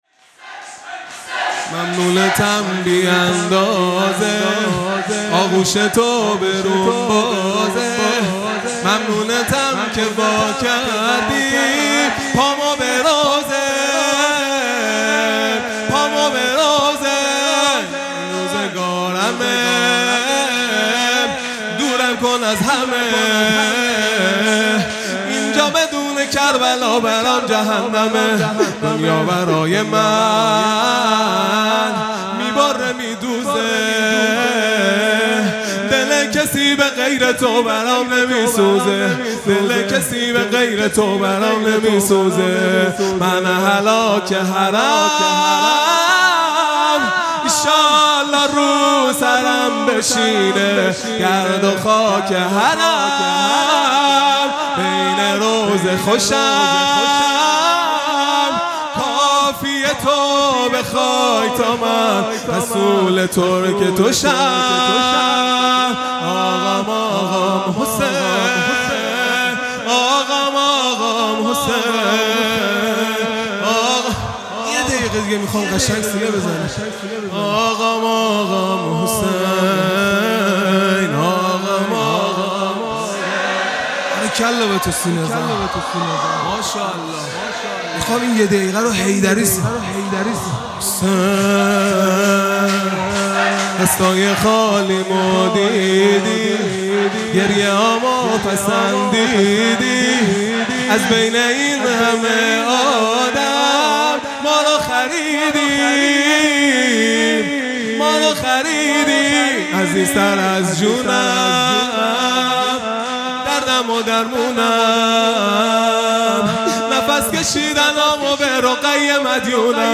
خیمه گاه - هیئت بچه های فاطمه (س) - شور | ممنونتم بی اندازه | 1۲ مرداد ۱۴۰۱
محرم ۱۴۴۴ | شب ششم